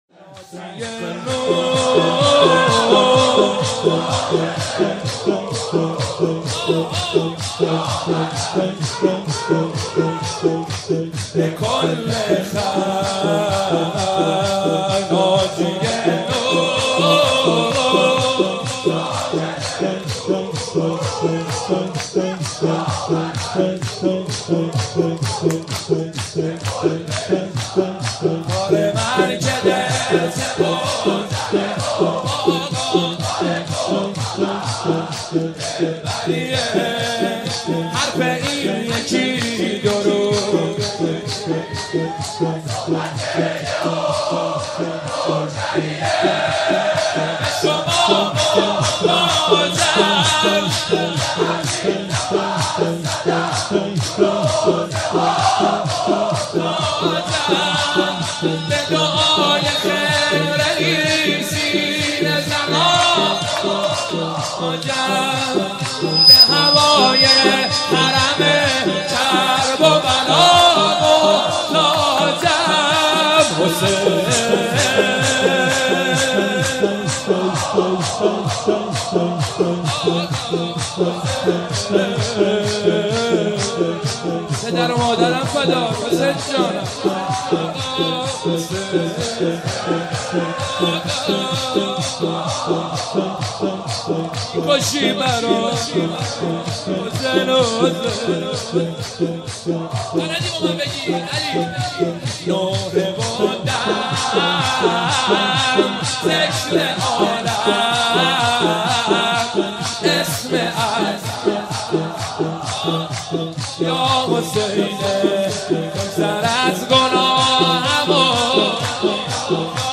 مناسبت : شب نوزدهم رمضان - شب قدر اول
قالب : شور